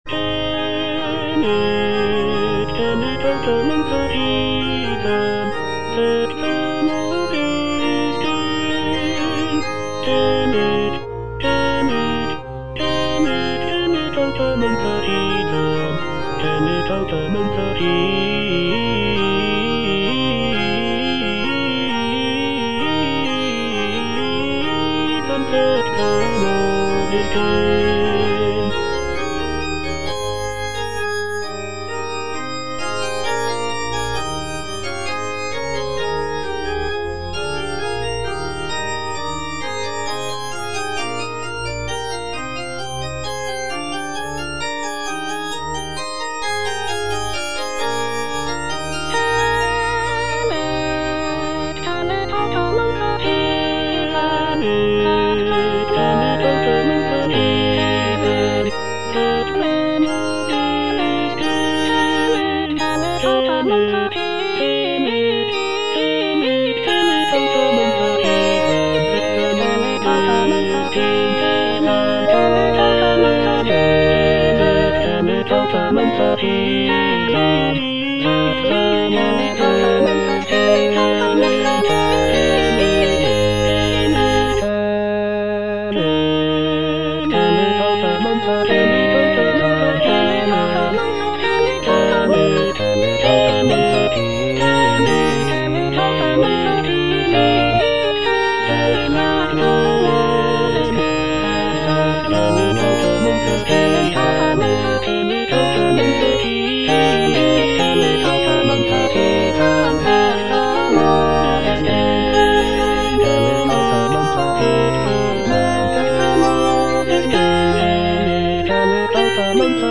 (A = 415 Hz)
(All voices)
sacred choral work